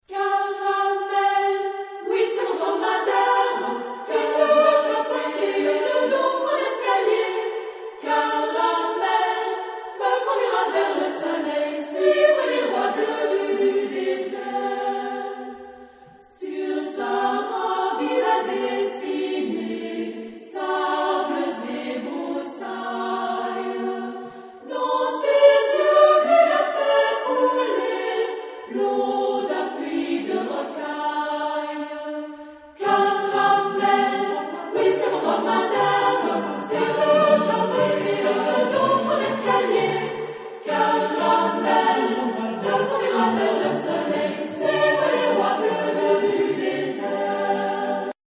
Genre-Style-Forme : Variété ; Profane ; Chanson ; Marche
Tonalité : sol majeur ; ré majeur
Consultable sous : Populaire Francophone Acappella